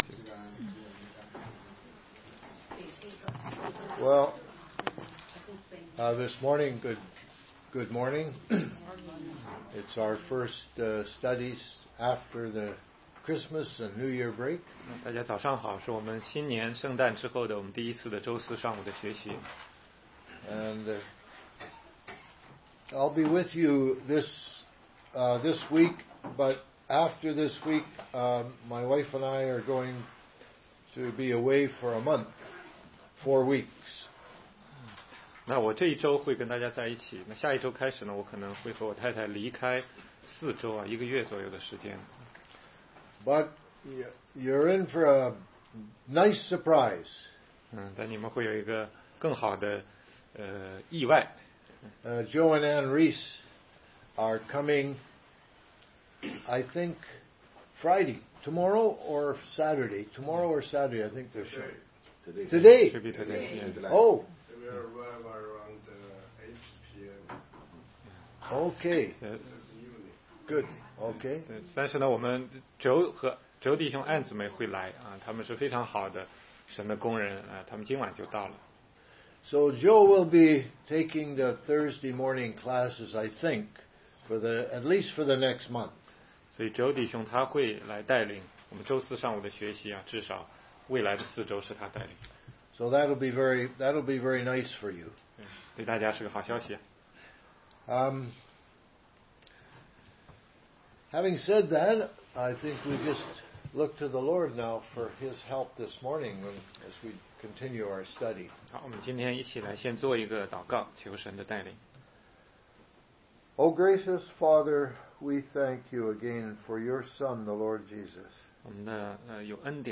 16街讲道录音 - 怎样才能读懂圣经系列之十八